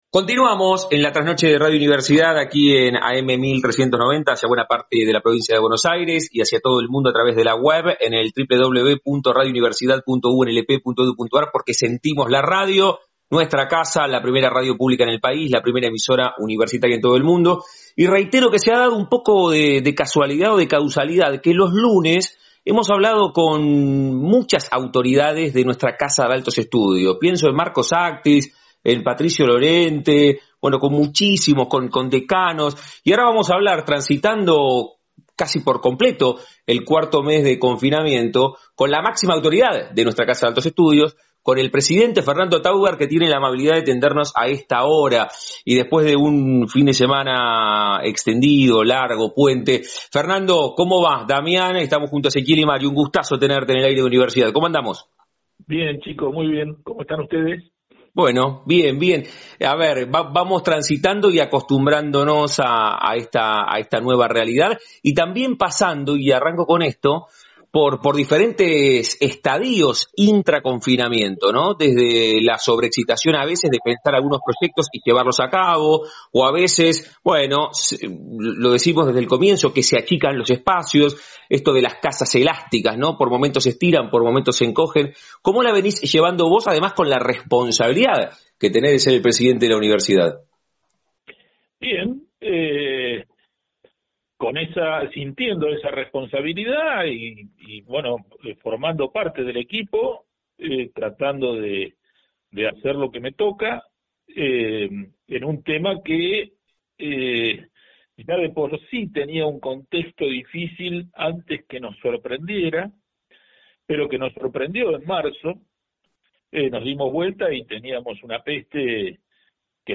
Comparto la entrevista que me realizaron en el programa de la trasnoche de Radio Universidad el día lunes 13 de julio del año 2020, donde expliqué los ejes rectores de la actividad de la UNLP durante la pandemia y los compromisos a futuro con la comunidad de la región.